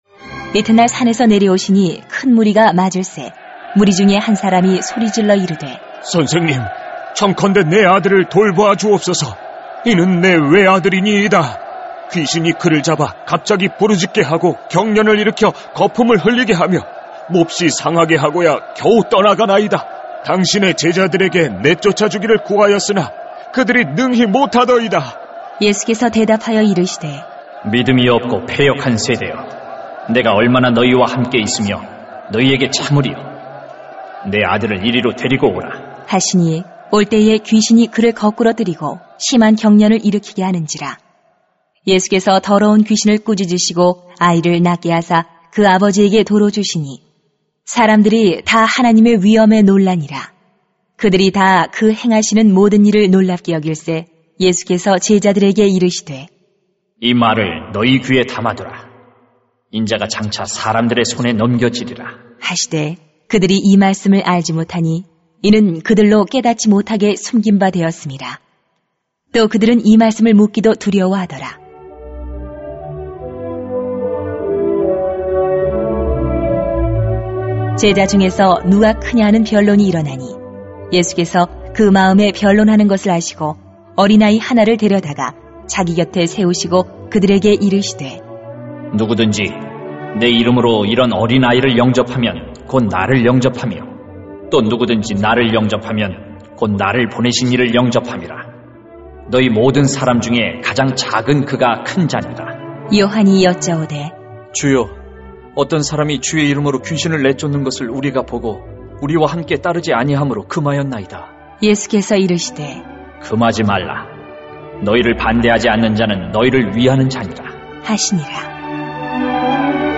[눅 9:37-50] 예수님과 함께 가야 할 길 > 새벽기도회 | 전주제자교회